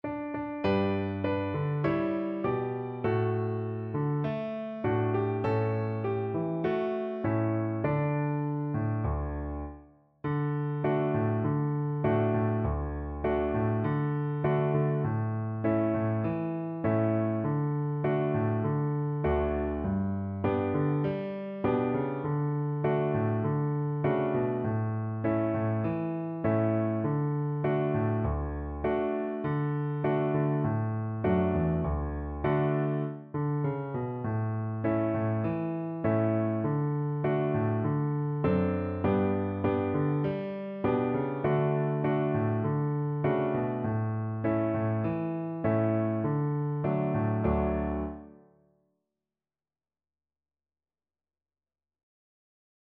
Cello
D4-D5
Moderato
4/4 (View more 4/4 Music)
Traditional (View more Traditional Cello Music)
world (View more world Cello Music)